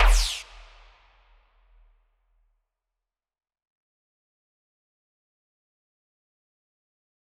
MDMV3 - Hit 20.wav